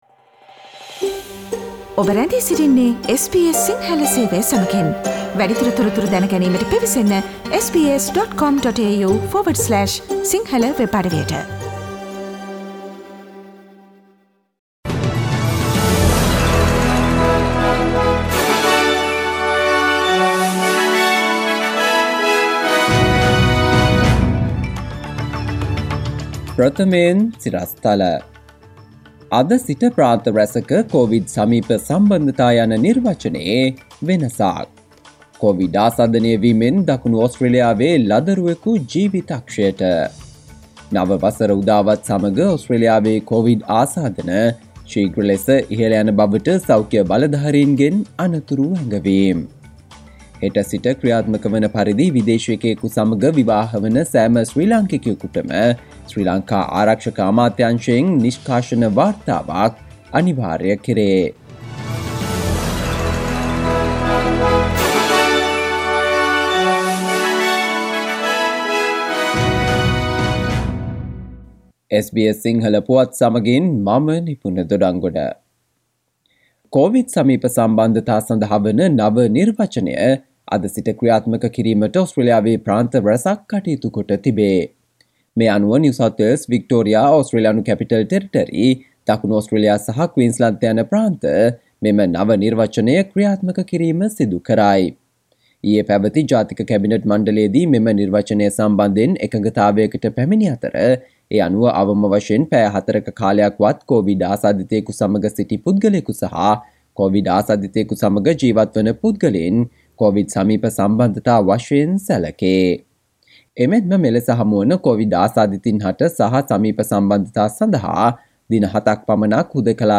SBS Sinhala Radio News on 31 Dec 2021: Australia is on the rise of COVID cases with the New year, warns health officials